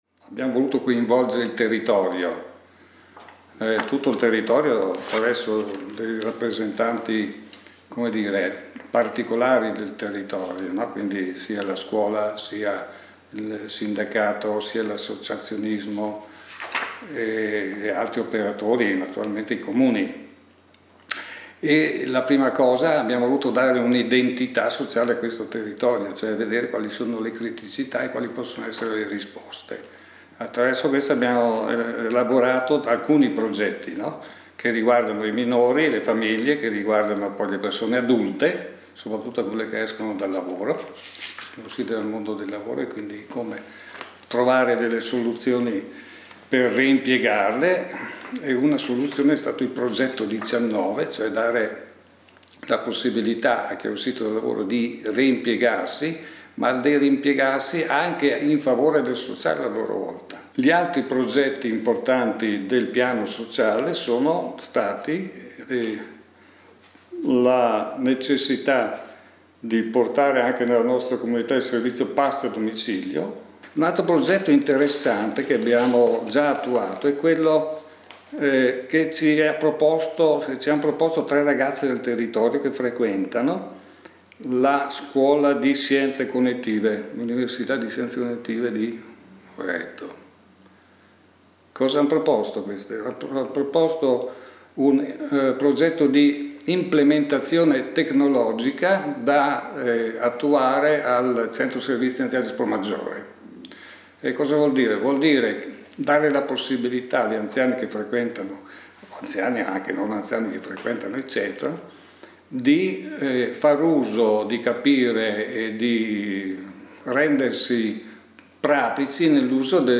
Presentazione audio dell'assessore Claudio Dal Rì